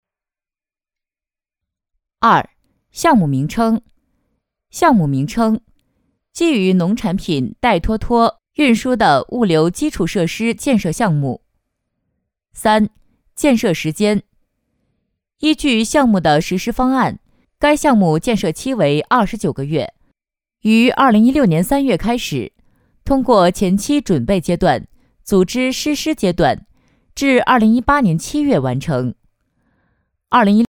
100%人工配，价格公道，配音业务欢迎联系：
B女78号
【专题】报告类 施工建设项目